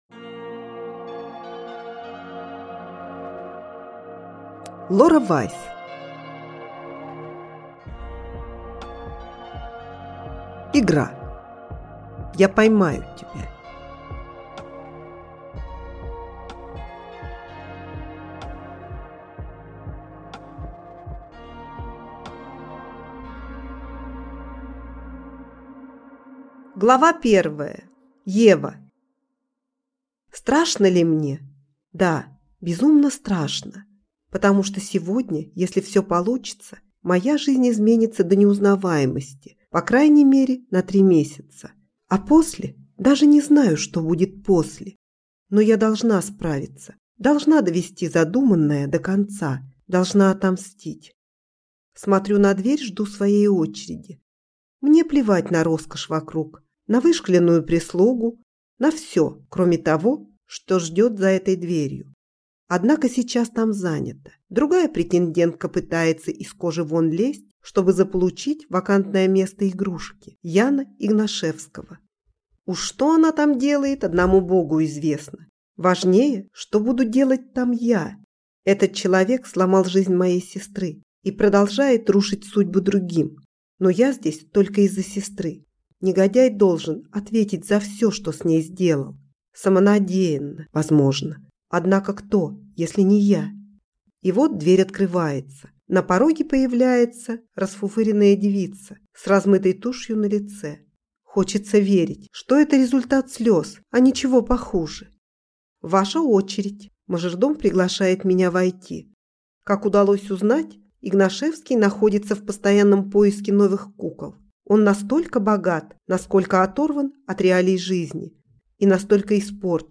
Аудиокнига Игра. Я поймаю тебя | Библиотека аудиокниг